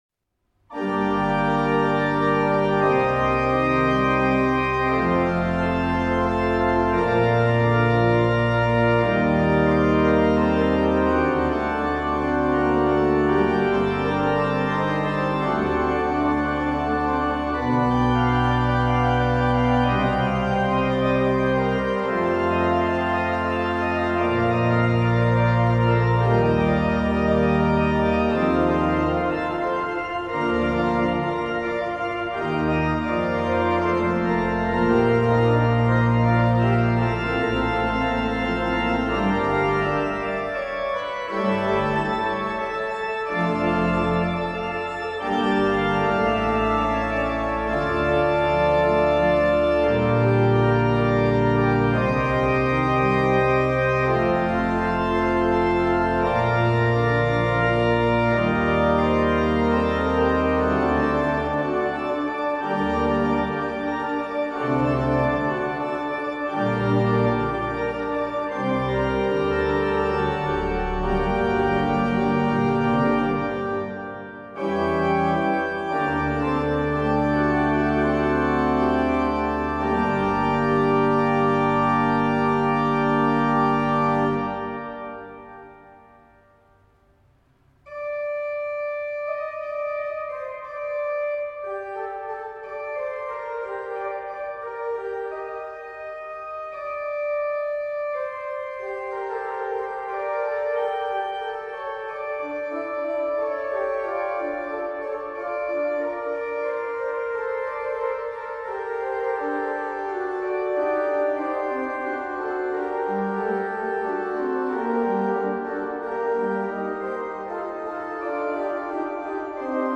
Die Orgel der Heilig-Geist-Kirche